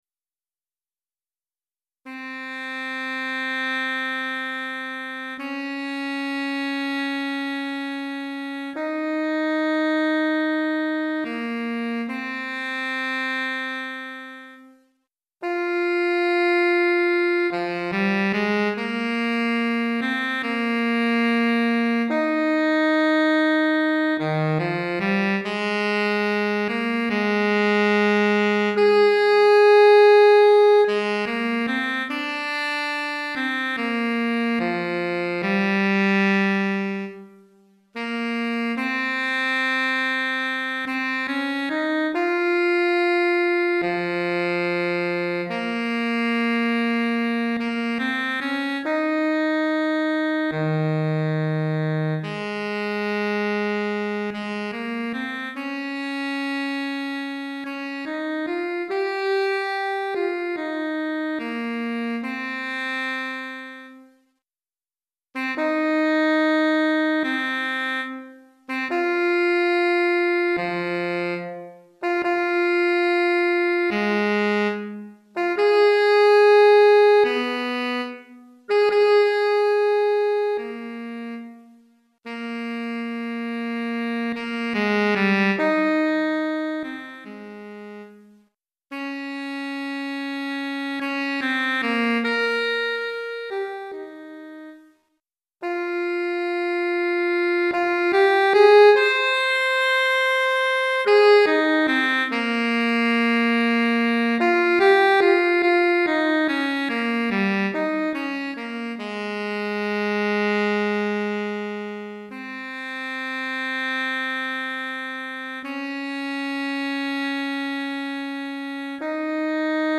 Saxophone Alto Solo